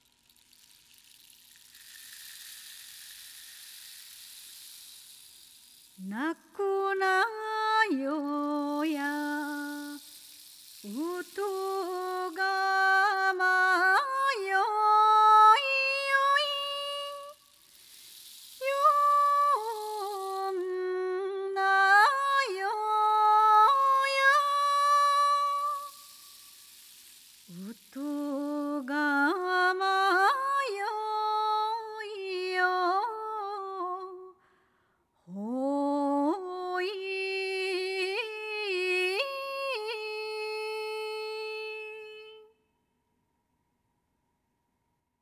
アカペラverはこちらから
②ばんがむり（アカペラ＋波の音）.wav